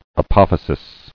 [a·poph·y·sis]